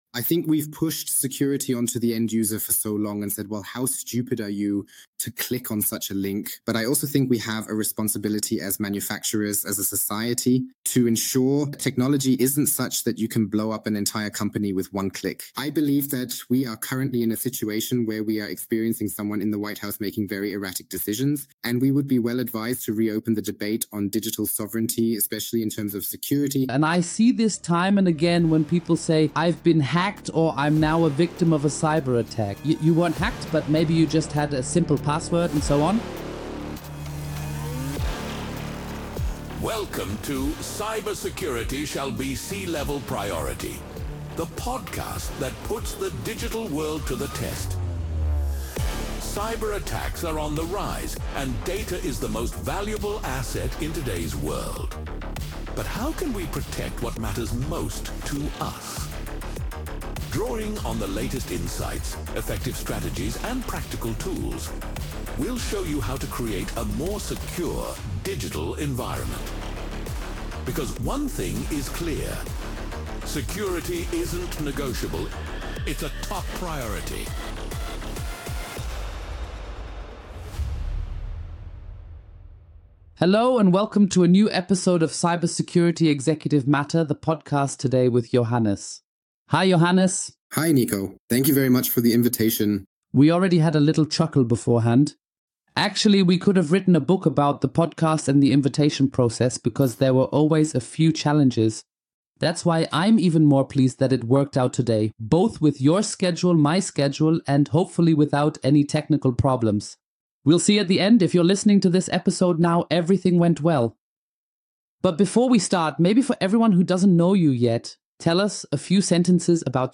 Webpage ____________________________________________ 🚨 This Podcast is translated from the original content Cybersecurity ist Chefsache using AI technology to make them accessible to a broader audience.